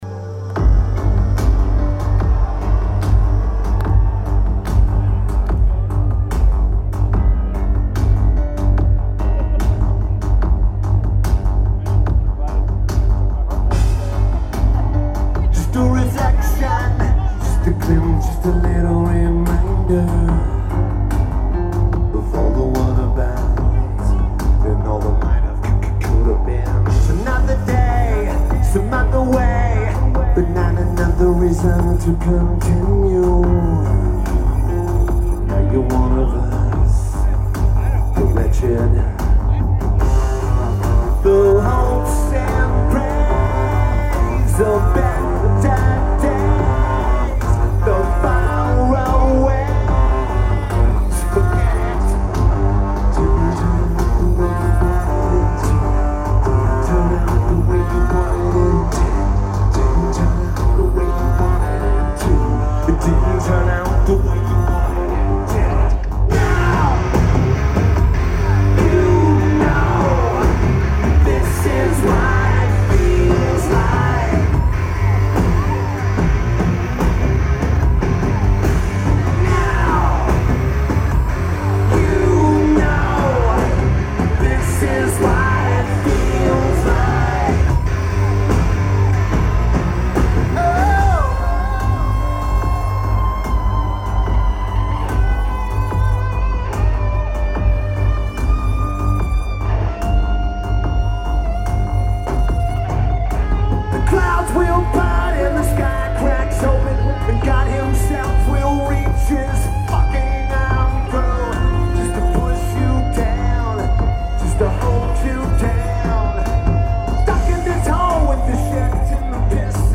Santa Barbara Bowl
Drums
Guitar